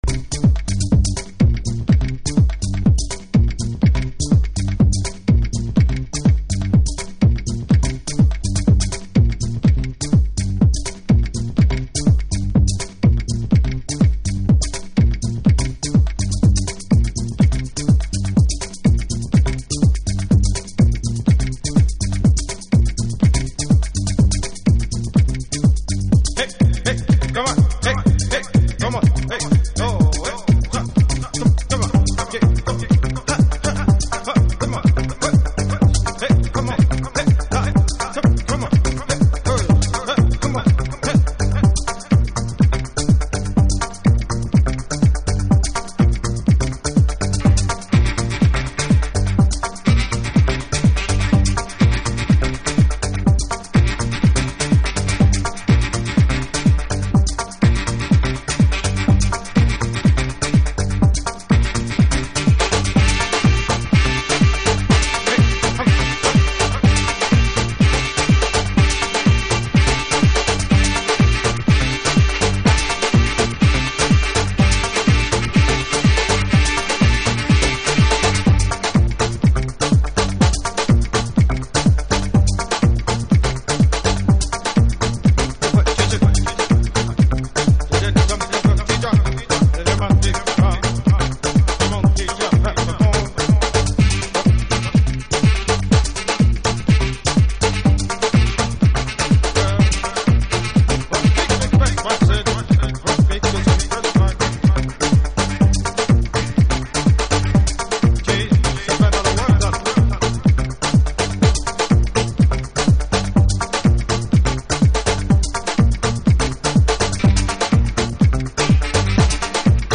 シンプルなトラック数で威力バツグンの鳴りを聴かせてくれるマシーンアフロリズムは健在。